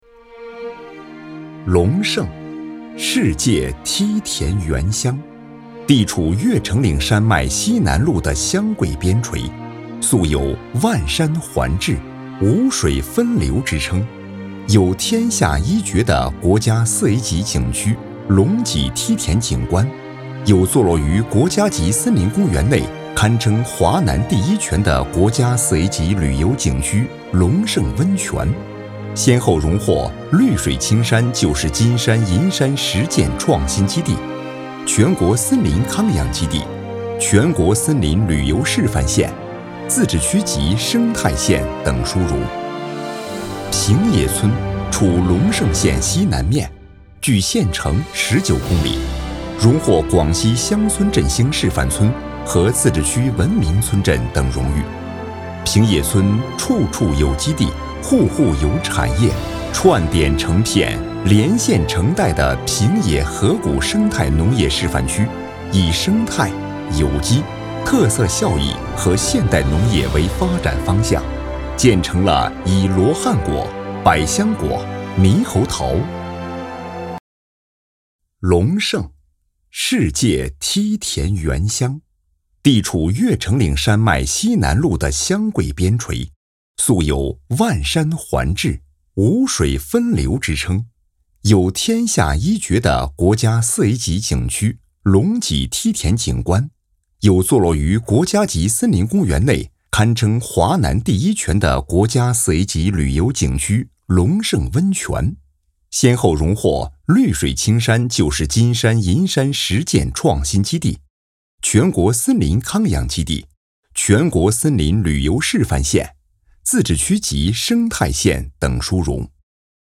中文男声
• 宣传片
• 大气
• 沉稳